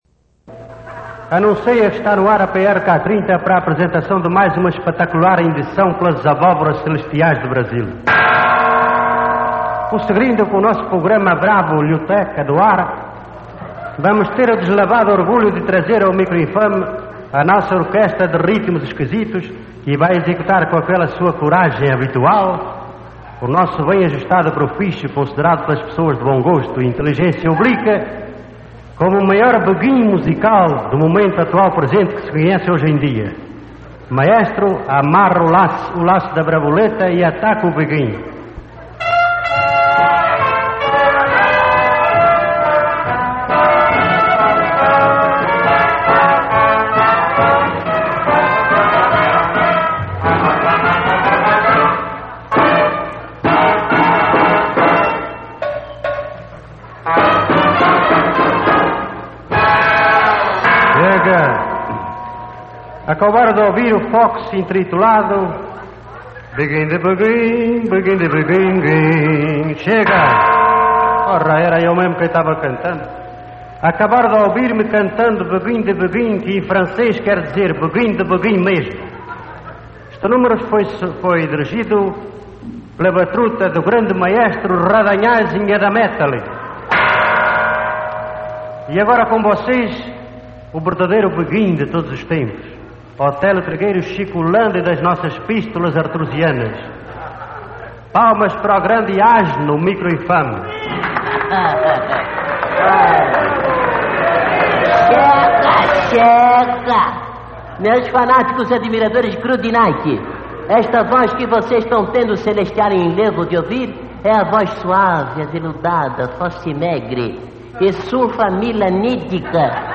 Este foi, sem dúvida, o maior programa de rádio de todos os tempos no Brasil.
Dois dos maiores humoristas brasileiros, Lauro Borges e Castro Barbosa estavam a frente desse programa de humor, que estreou na rádio Mayrink Veiga, no Rio de Janeiro, em 19 de outubro de 1944. PRK-30 era o prefixo de uma suposta rádio pirata, onde dois speakers, Megatério Nababo D`alicerce (Castro Barbosa) e Otelo Trigueiro (Lauro Borges), apresentavam notícias de impagáveis correspondentes internacionais, cantores internacionais, calouros, todos protagonizados pelos dois humoristas.